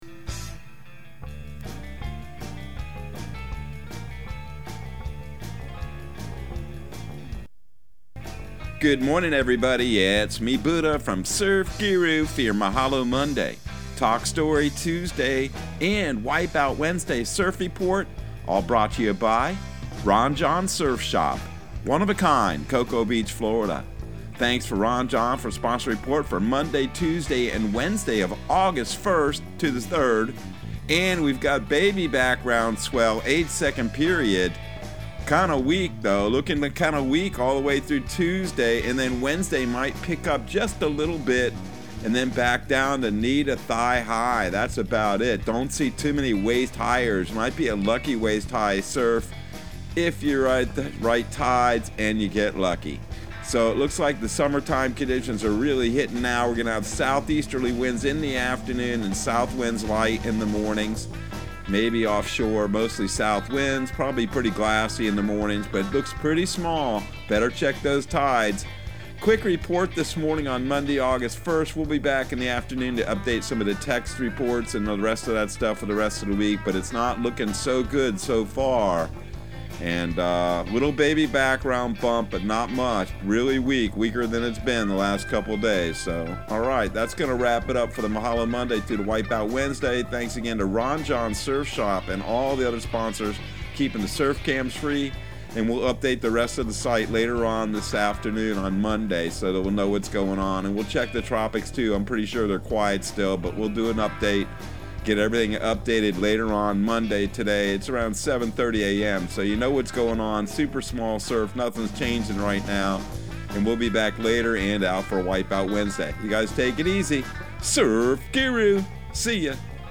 Surf Guru Surf Report and Forecast 08/01/2022 Audio surf report and surf forecast on August 01 for Central Florida and the Southeast.